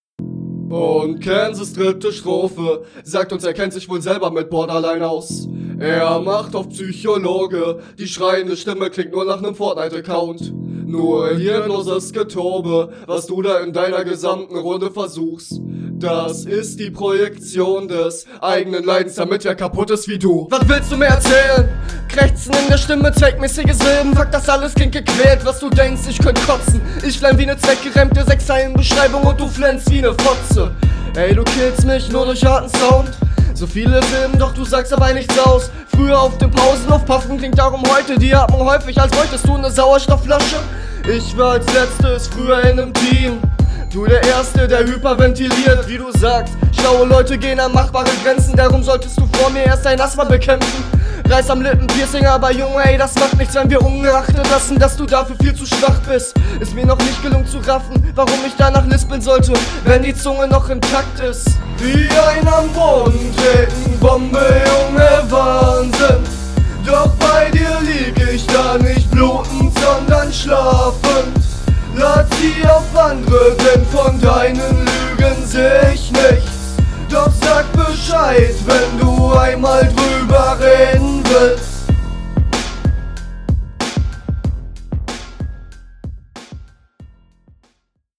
intro sehr interessant geflowed und auch danach kommst du gut auf den beat. dein mix …